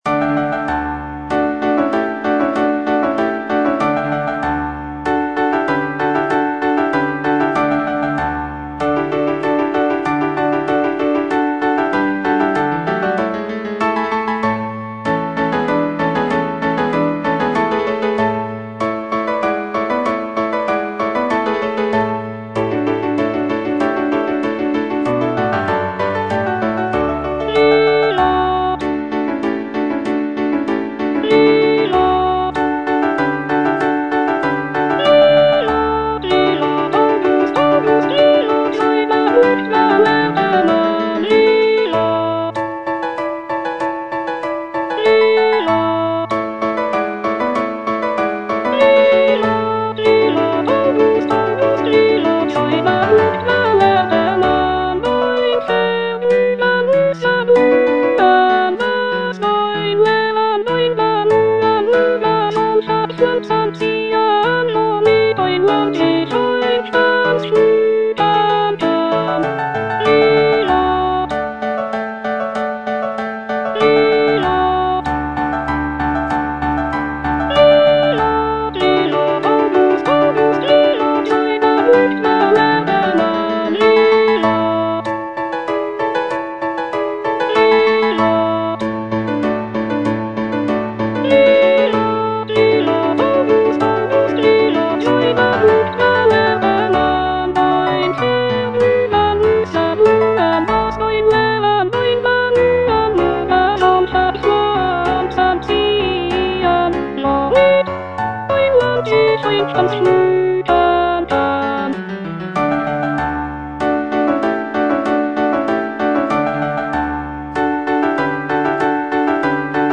The music is lively and celebratory, with intricate counterpoint and virtuosic vocal lines.